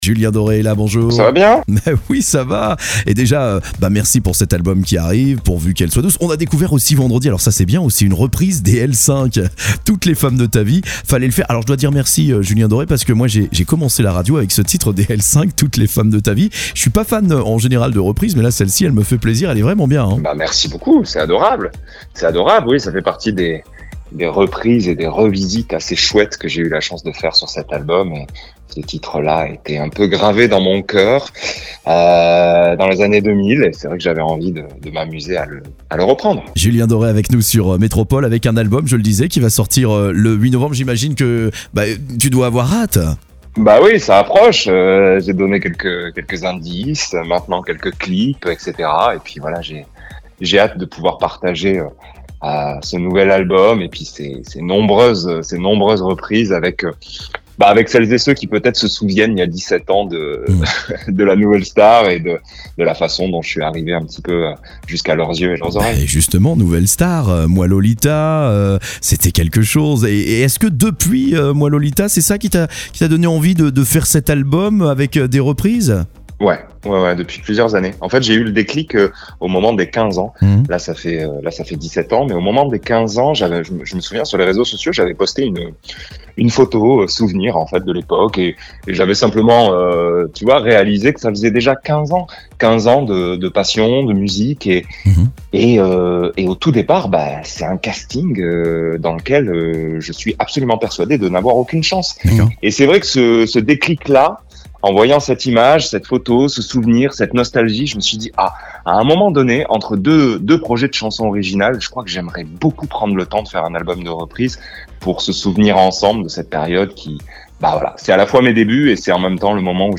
Julien Doré en interview sur Metropole